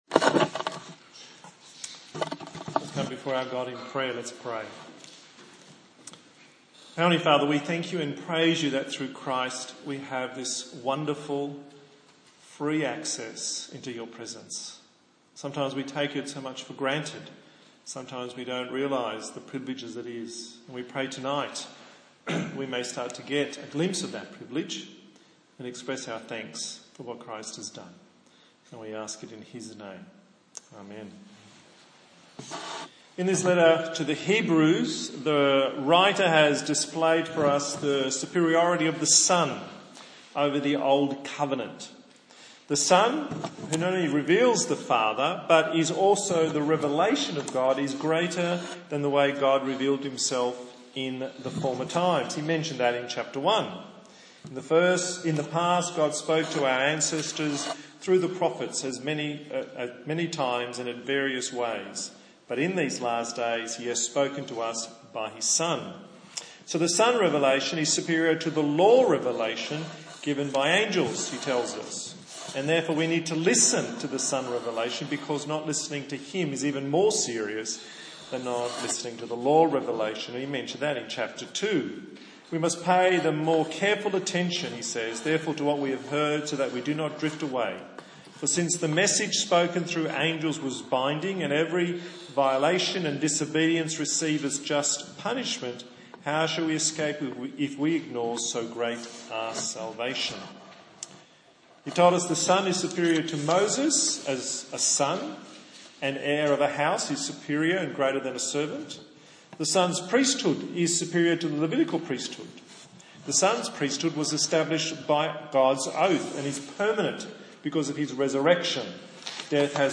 A sermon in the series on the book of Hebrews